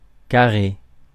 Ääntäminen
France (Paris): IPA: [ka.ʁe]